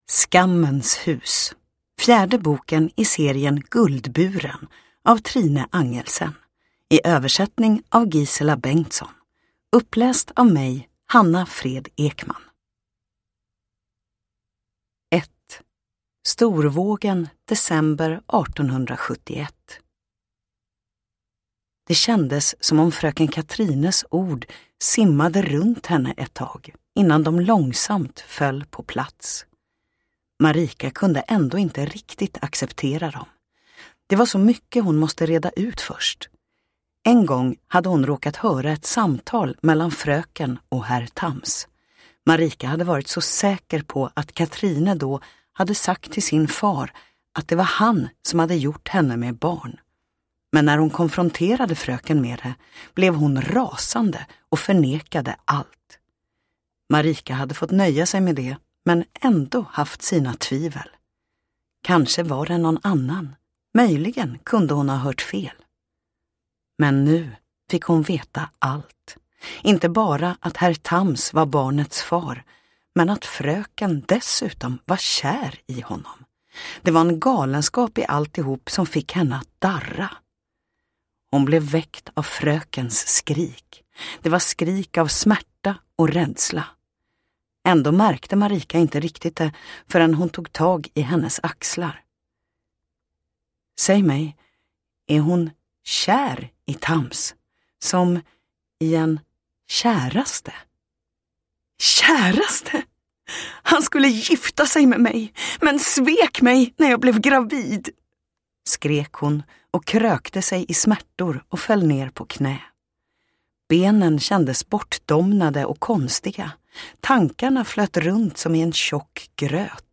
Skammens hus – Ljudbok